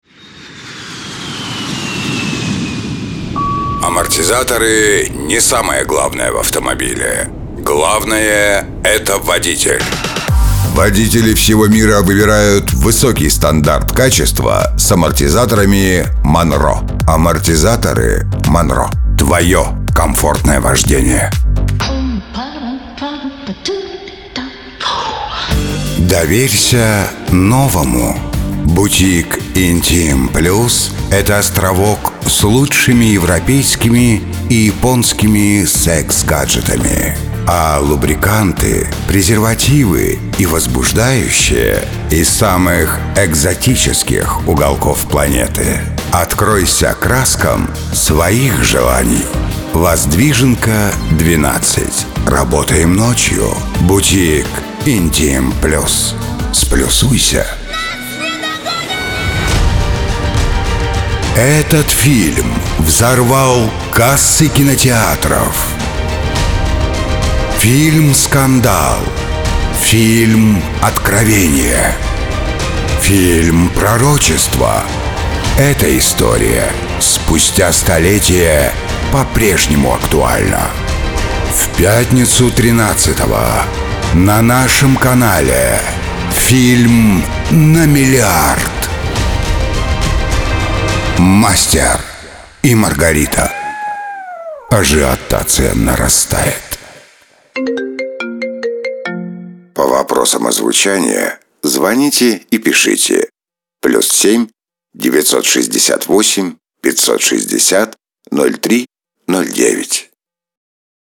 вк-демо-голос-реклама
Муж, Рекламный ролик/Зрелый